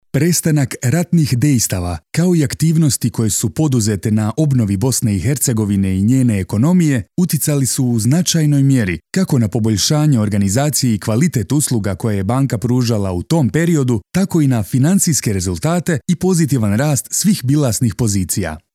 Boşnakca Seslendirme
Erkek Ses